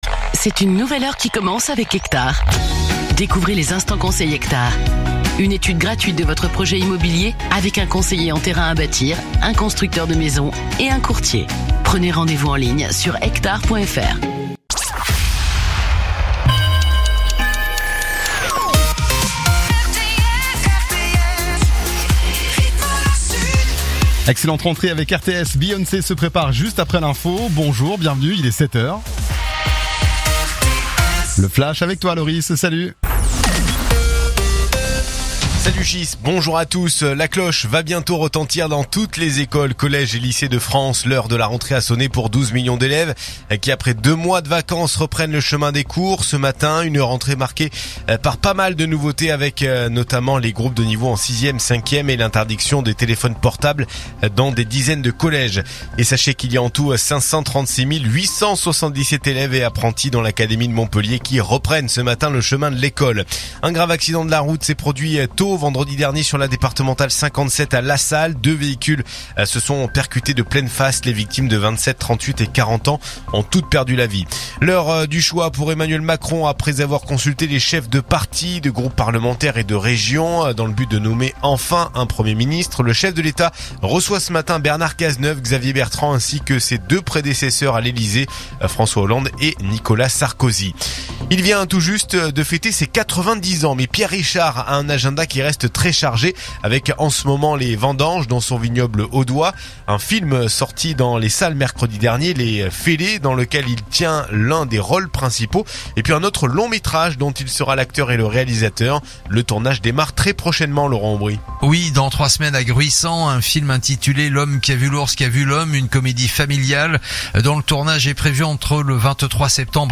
info_nimes_129.mp3